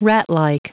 Prononciation du mot ratlike en anglais (fichier audio)
Prononciation du mot : ratlike